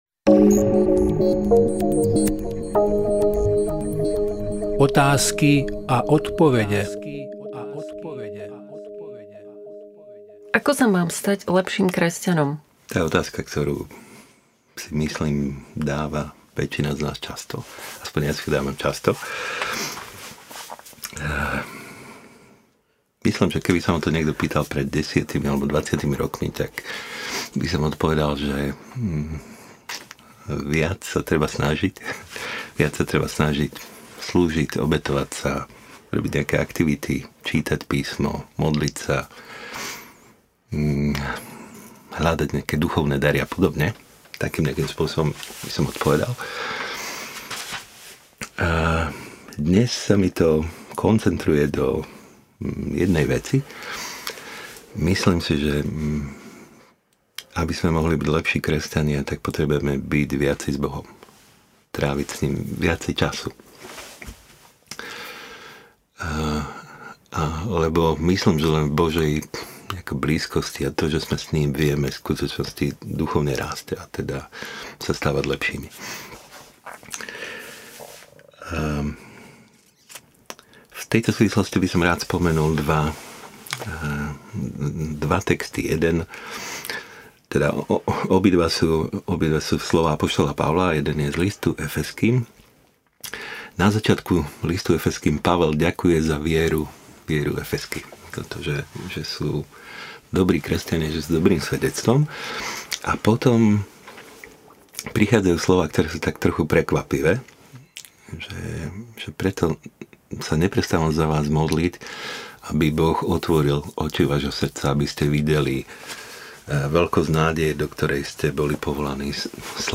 E-mail Podrobnosti Kategória: Rozhovory Séria